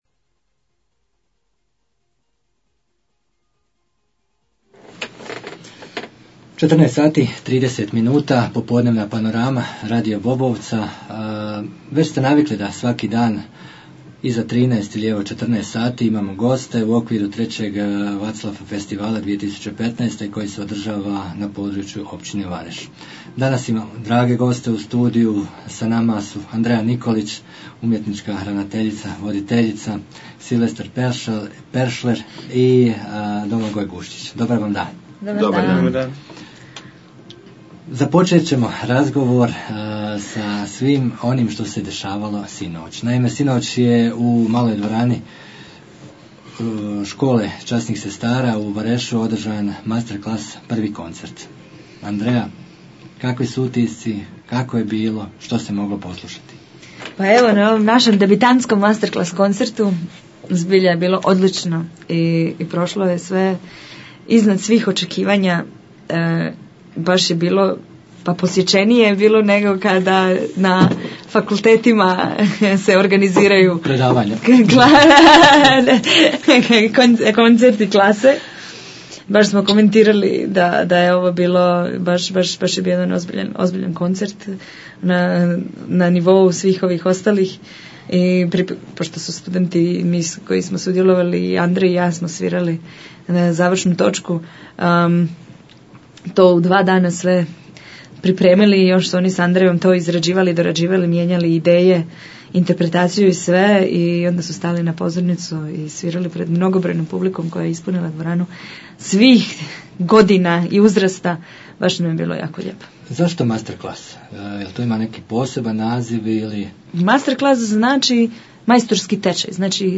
Pred nama je završnica 3.VaClaF-a, tim povodom gosti u studiju su bili: